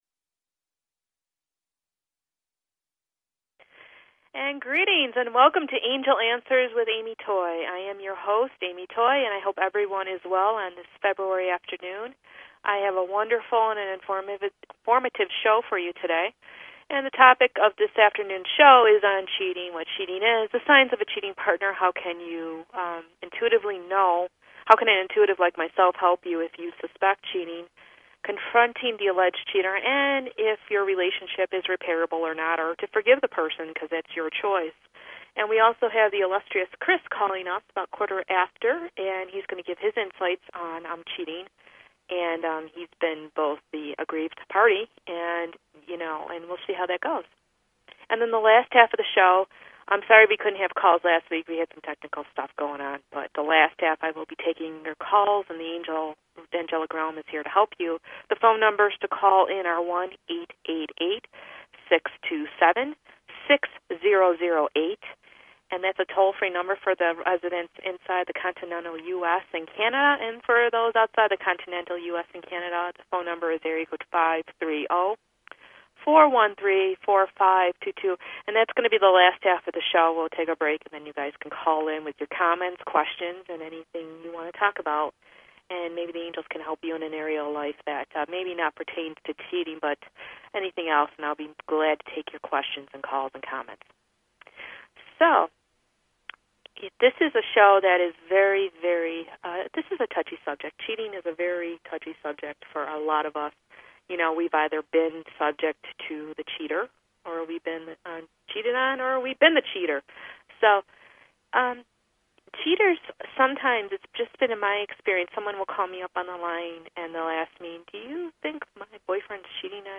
Talk Show Episode, Audio Podcast, Angel_Answers and Courtesy of BBS Radio on , show guests , about , categorized as